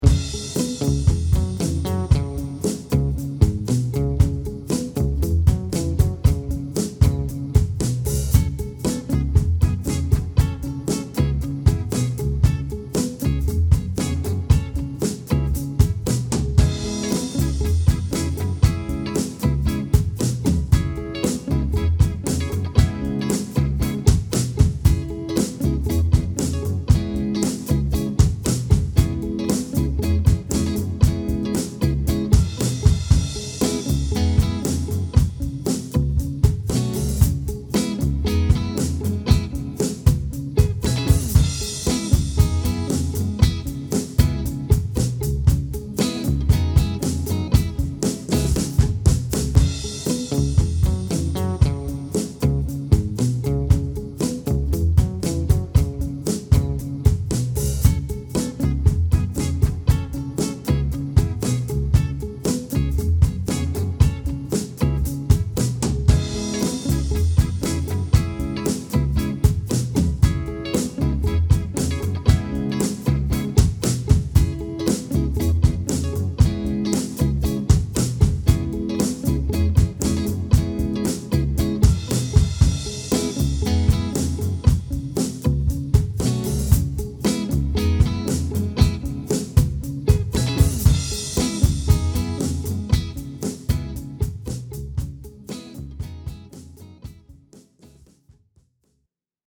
Just a quick loop that we put together..
on bass and guitar in one take with one guitar and one amp